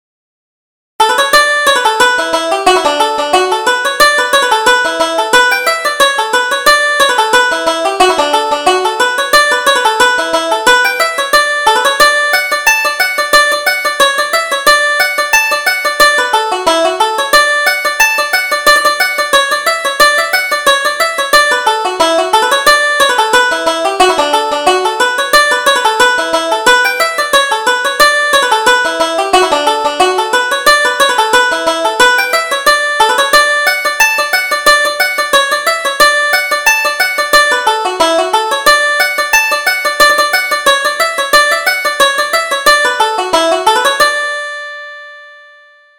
Reel: The Old Pensioner